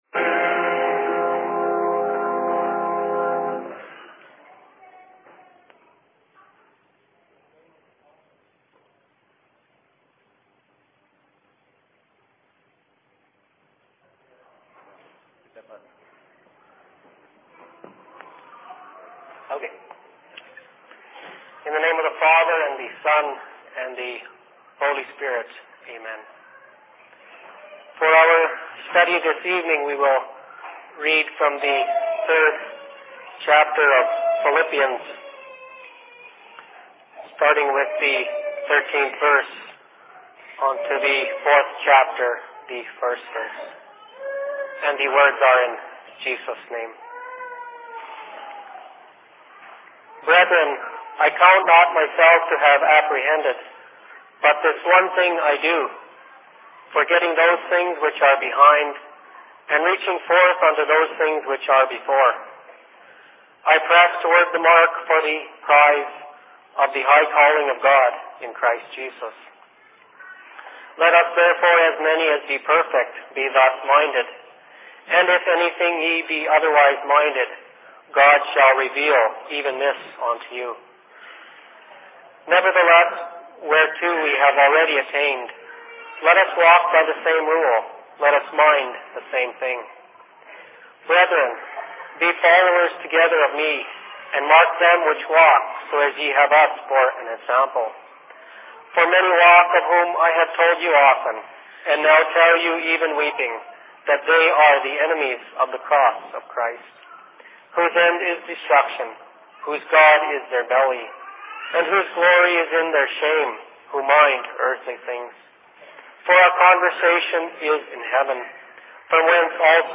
Thanks Giving services/Sermon in Minneapolis 24.11.2006
Location: LLC Minneapolis